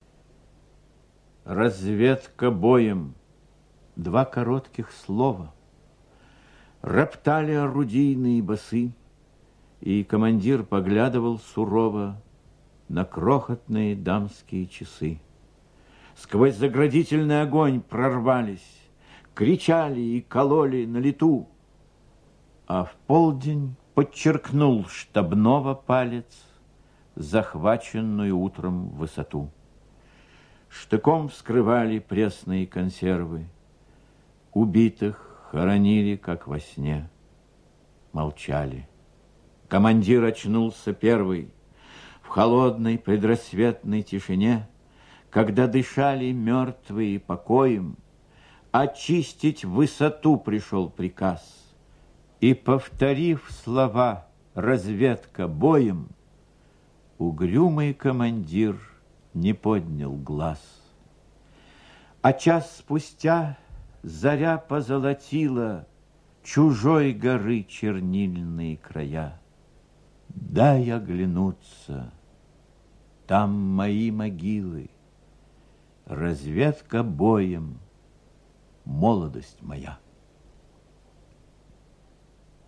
2. «И.Эренбург – Разведка боем (чит. А.Консовский)» /
Erenburg-Razvedka-boem-chit.-A.Konsovskij-stih-club-ru.mp3